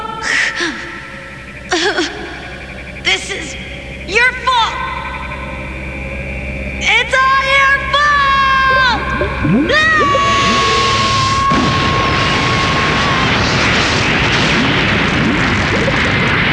Gohan Screaming in anger!